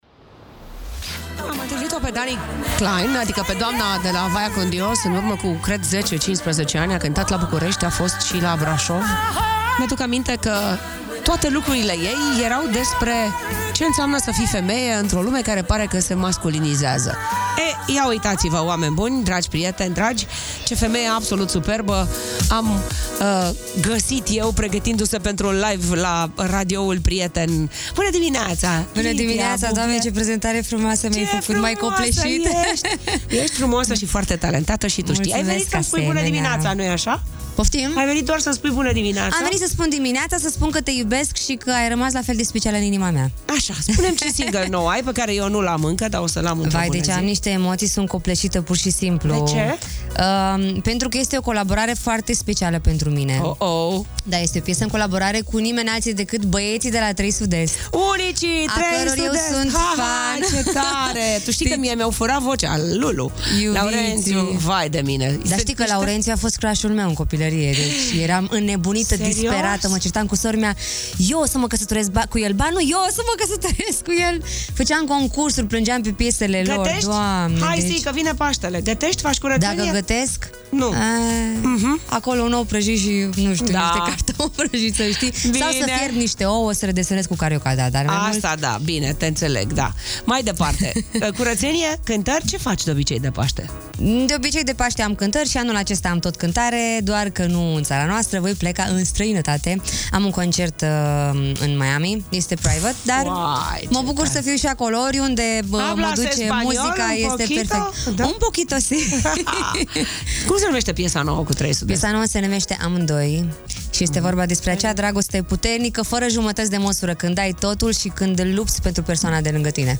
Magic Morning - 10 Aprilie - invitată, Lidia Buble LIVE